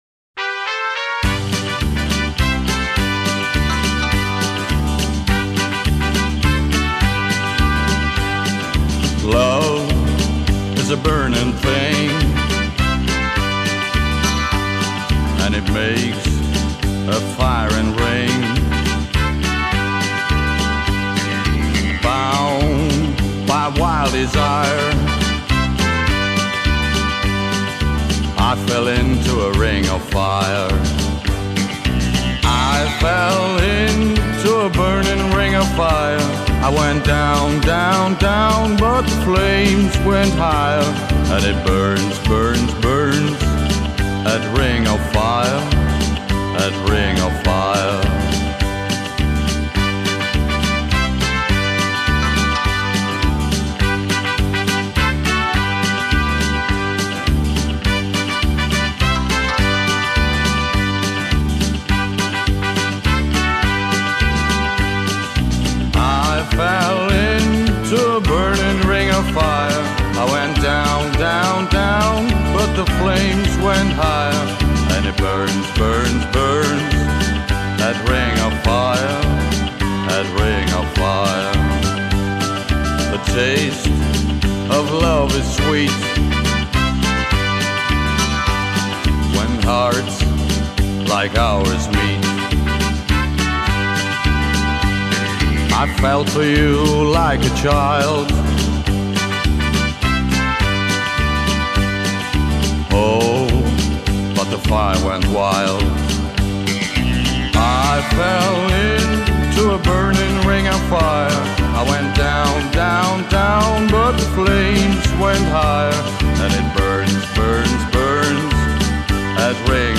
07 Quickstep